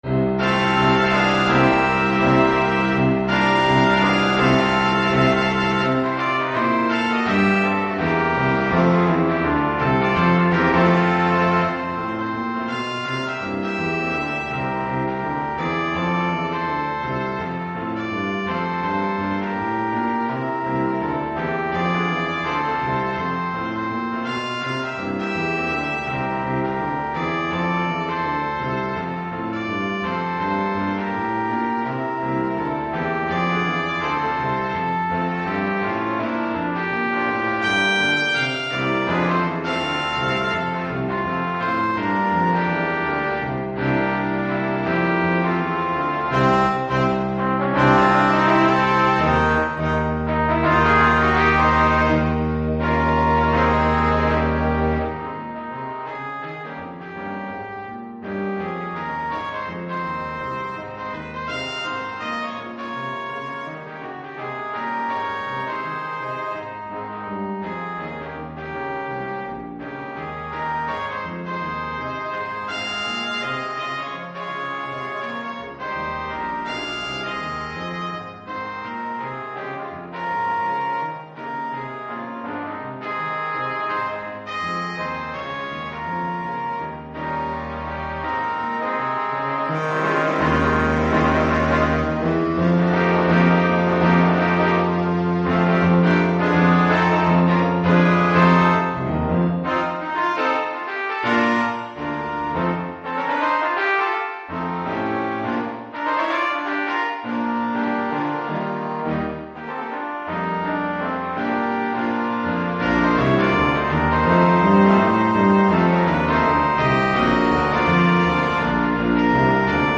Keyboard & Drums optional.
5-Part Ensemble Schwierigkeit
Ensemble gemischt PDF